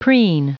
Prononciation du mot preen en anglais (fichier audio)
Prononciation du mot : preen